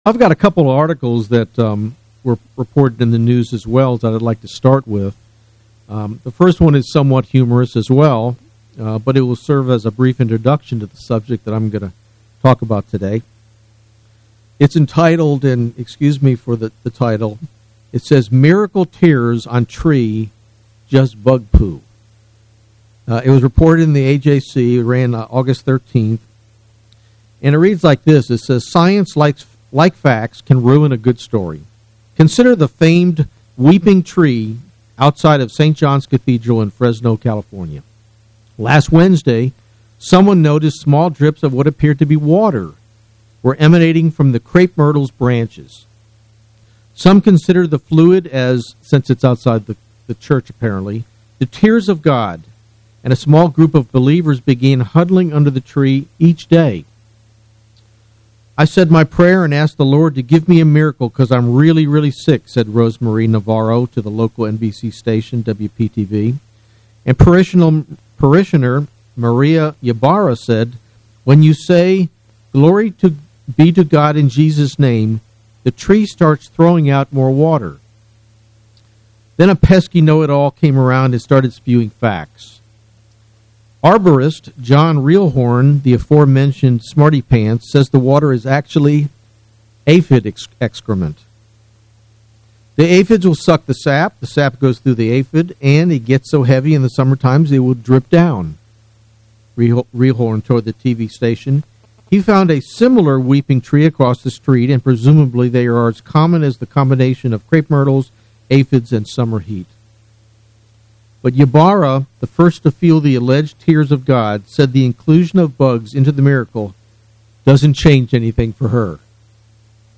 Given in Atlanta, GA
Can we use what God tells us about angels to come to correct understandings about the things which lead the world into confusion? UCG Sermon Studying the bible?